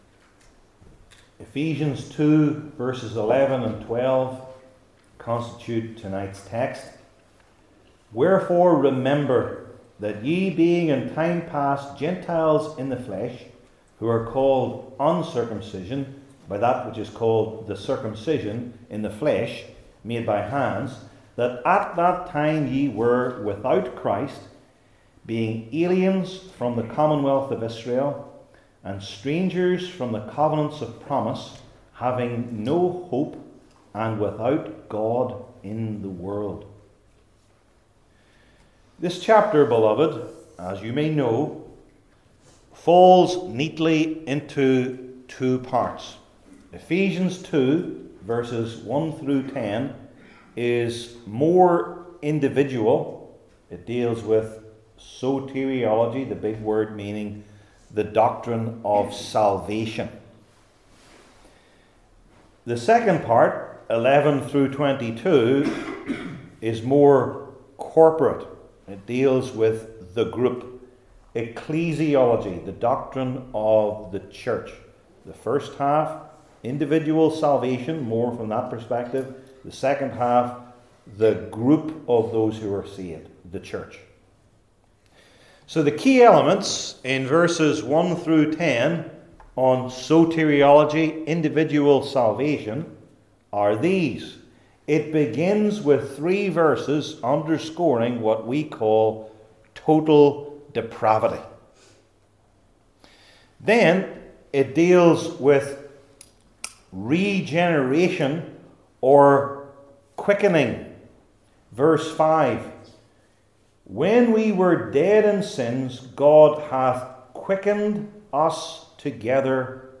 Ephesians 2:11-12 Service Type: New Testament Sermon Series I. What They Were Called II.